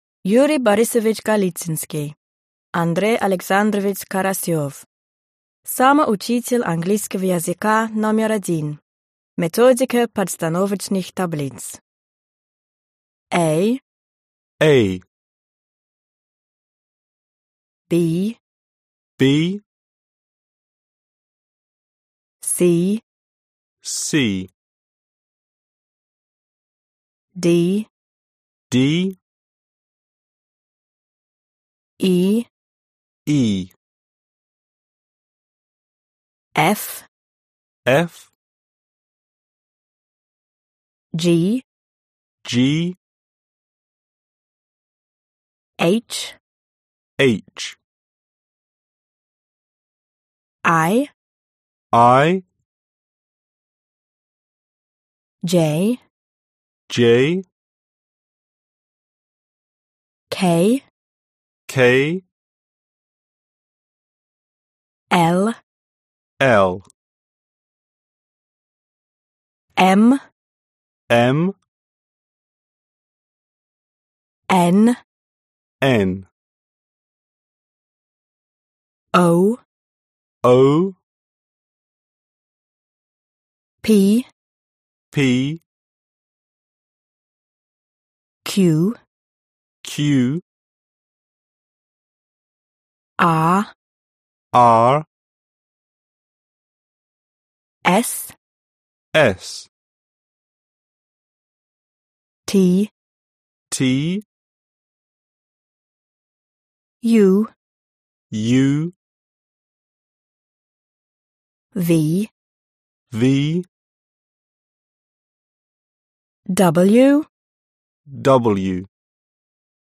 Аудиокнига Самоучитель английского языка №1. Книга 1. Уровень Elementary | Библиотека аудиокниг